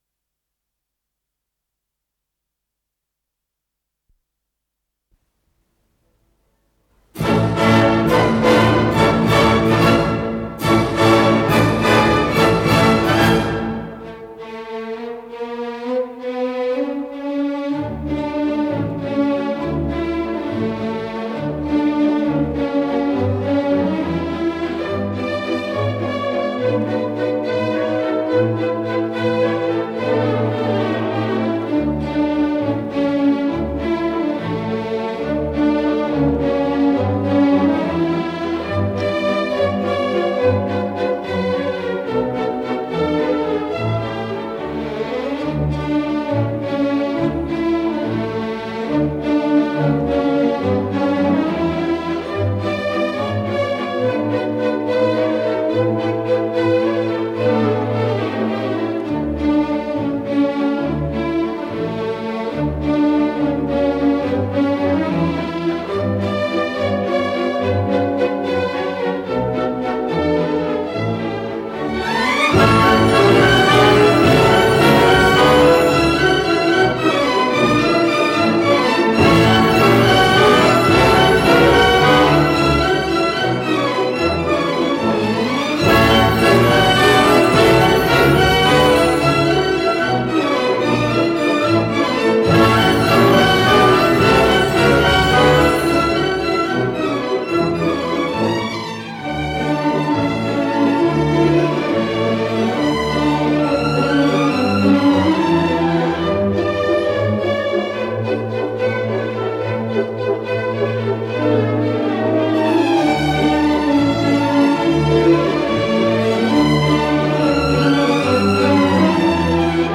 с профессиональной магнитной ленты
ПодзаголовокБалет в 4-х действиях, 1-е действие №5 Па-де-де
Содержание1. Темпо ди вальсе ма нон троппо виво, квази модерато
2. Анданте, Аллегро
ИсполнителиБольшой симфонический оркестр Всесоюзного радио и Центрального телевидения
Соло на скрипке
Художественный руководитель и дирижёр - Геннадий Рождественский
ВариантДубль моно